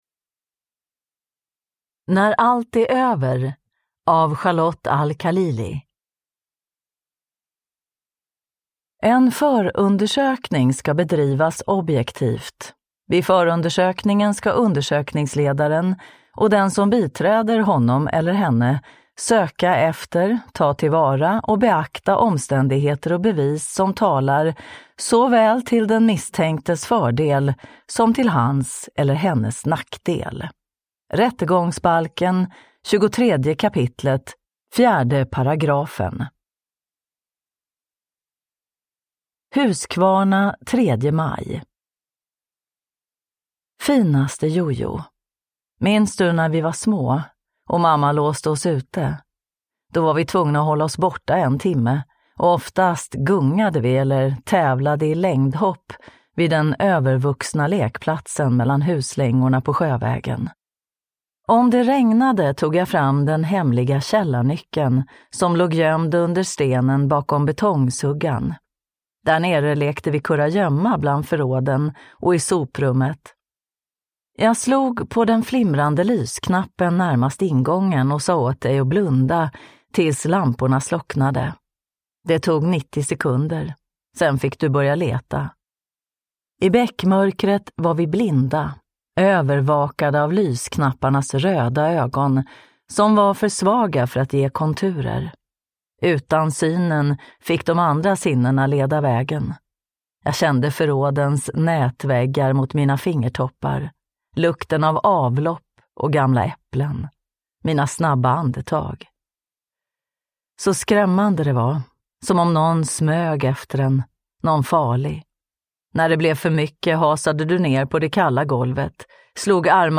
När allt är över – Ljudbok